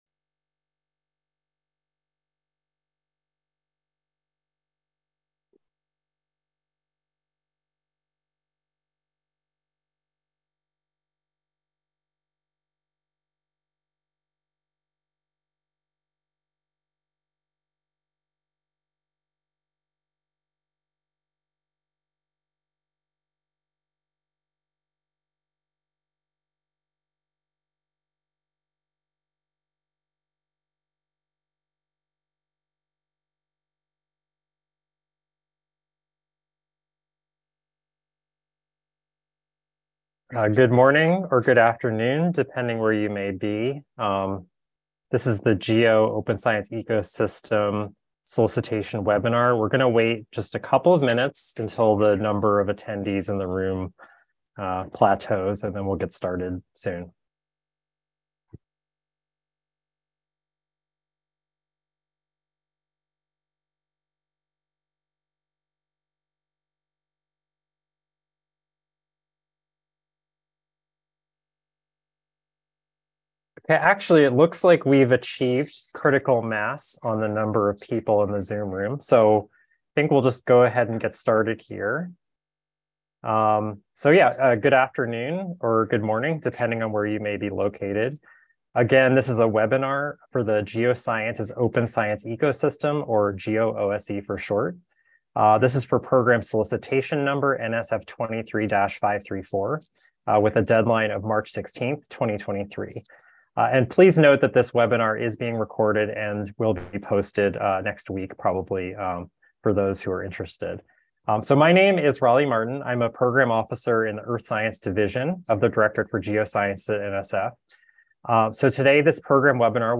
GEO Open Science Ecosystem (GEO OSE) Program Webinar